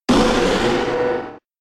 Cri de Méga-Flagadoss K.O. dans Pokémon X et Y.